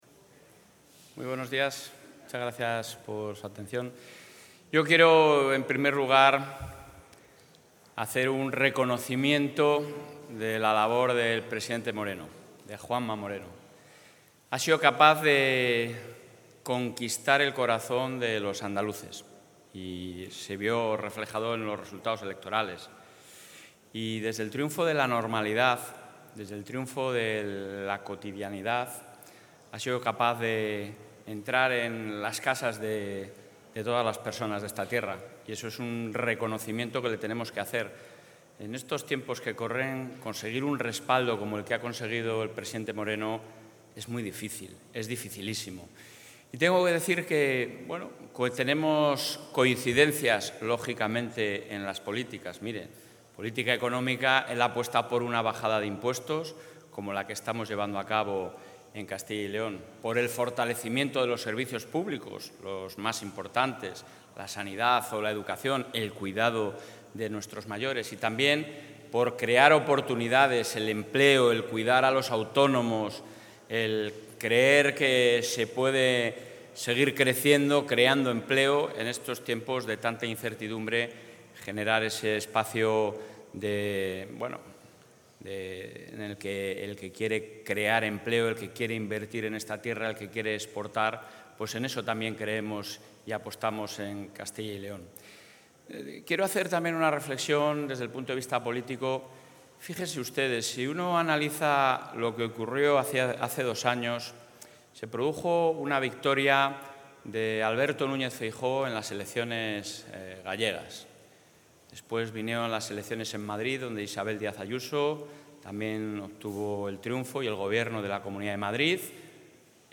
Material audiovisual de la asistencia del presidente de la Junta a la toma de posesión del presidente de la Junta de Andalucía
Audio presidente.